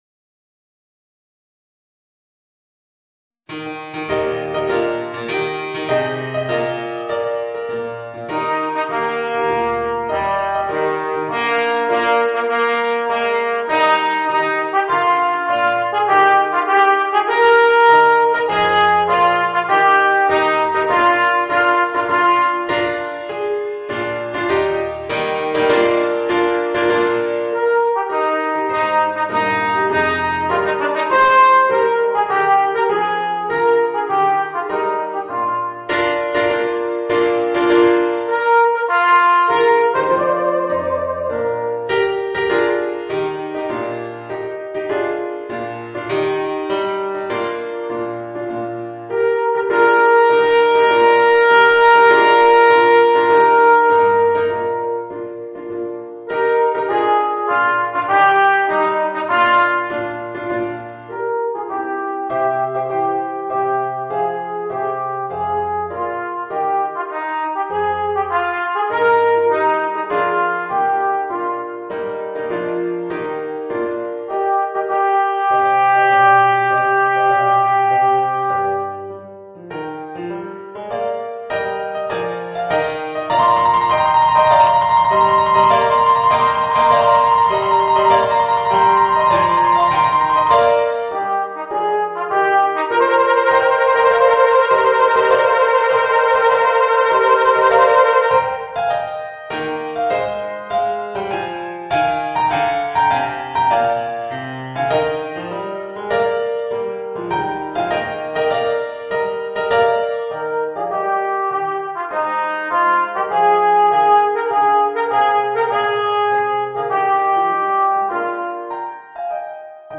Voicing: Alto Trombone and Organ